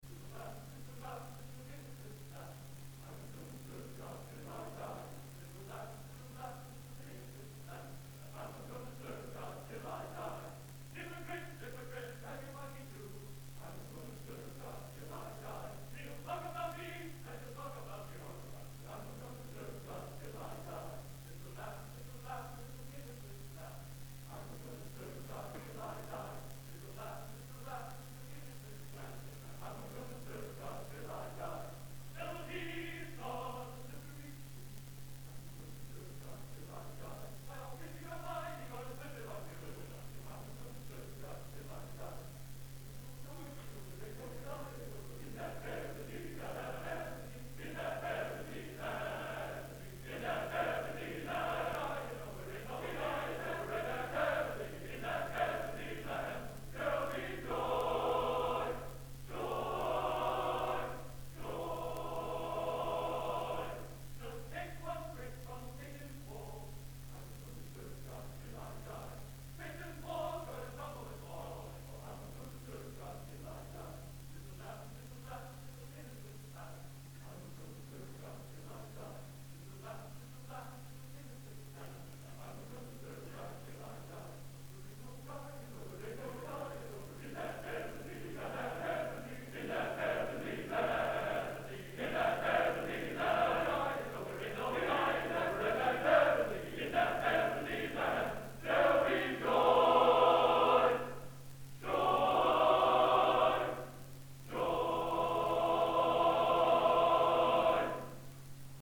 Genre: Spiritual | Type: